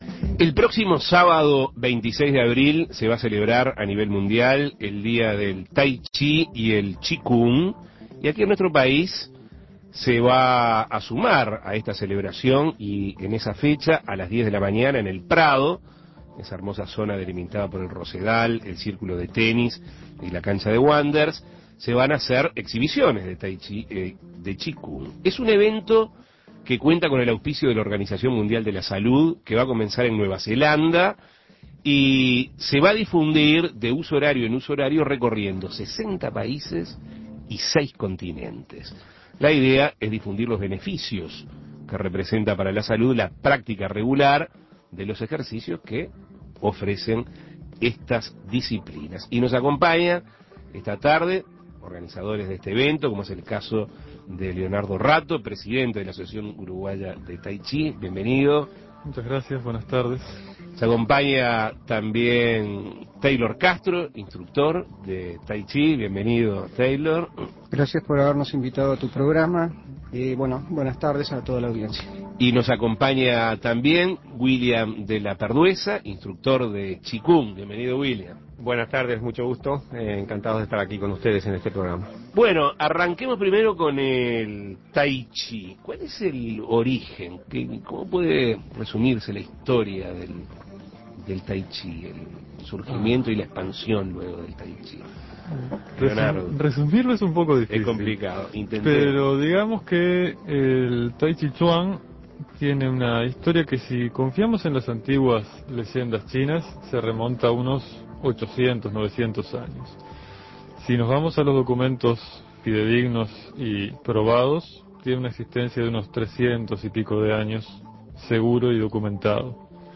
Entrevistas Actividades especiales por el Día Mundial del Tai Chi y el Chi Kung Imprimir A- A A+ El próximo sábado 26 de abril se celebrará el Día Mundial del Tai Chi y el Chi Kung.